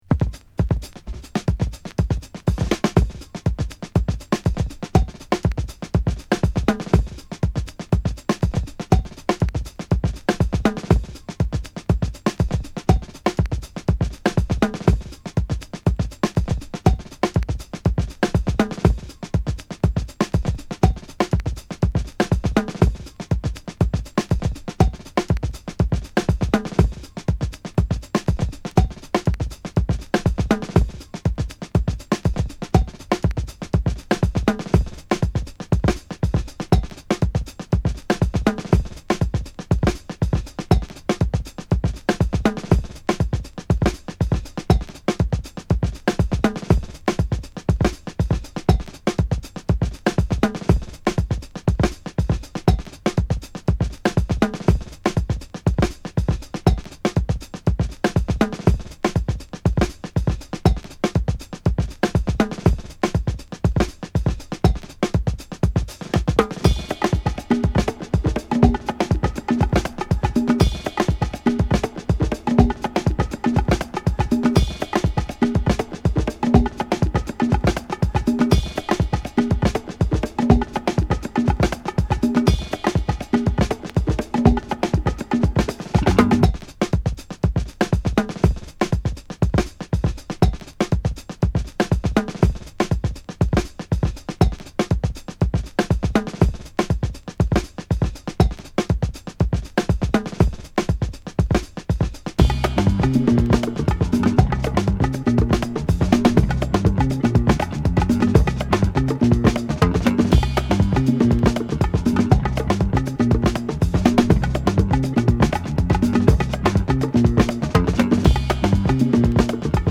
ラテン・パーカッシヴなブレイクビーツ的リズムと鮮やかなピアノ＆フルート・プレイが光る
両サイド共にDJユースなロングエディットが最高！